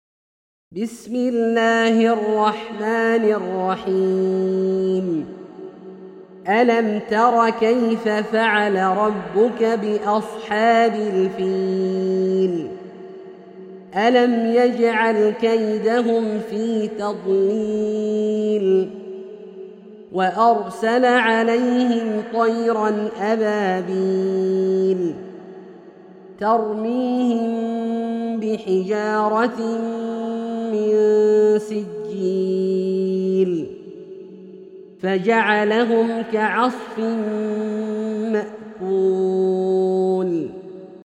سورة الفيل - برواية الدوري عن أبي عمرو البصري > مصحف برواية الدوري عن أبي عمرو البصري > المصحف - تلاوات عبدالله الجهني